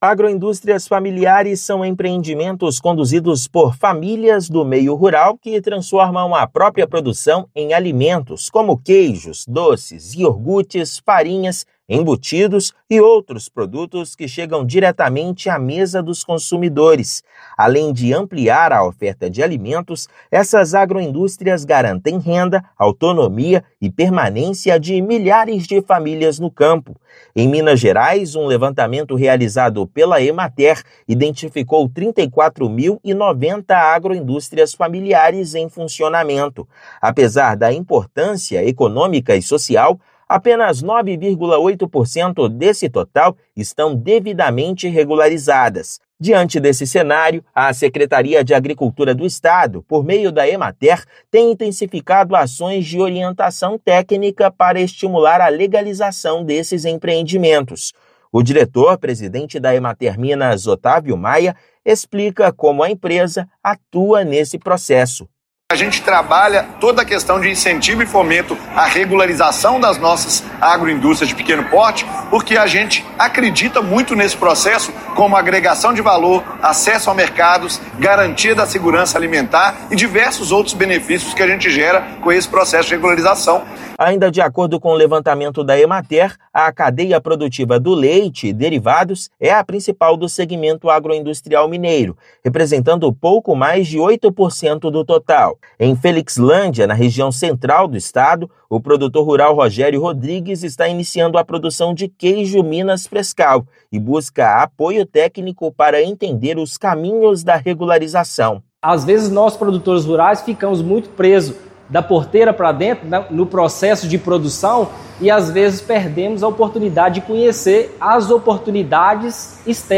Ações estão sendo intensificadas pela Seapa, por meio da Emater. O processo de formalização agrega valor, acesso a mercados e garantia da segurança alimentar. Ouça matéria de rádio.